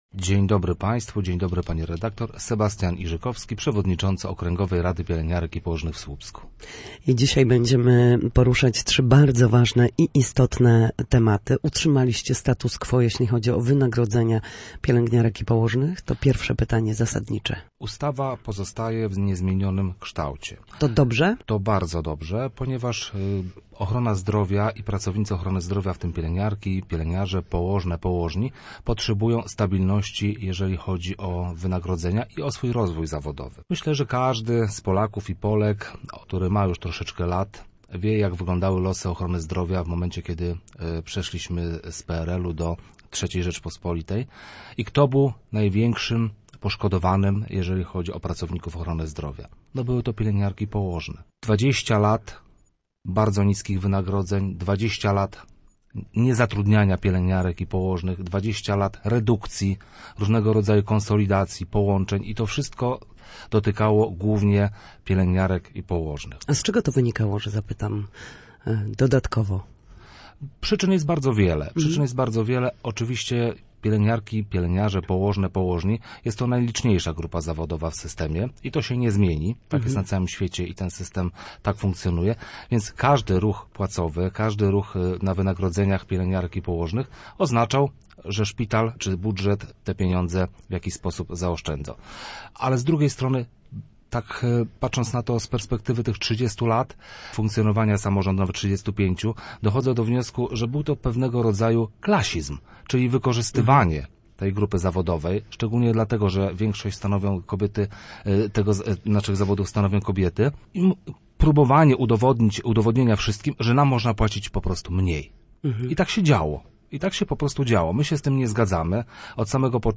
Walka o utrzymanie systemu wynagradzania pielęgniarek i położnych nie została zakończona, jedynie weszła w kolejny etap - podkreślał w Studiu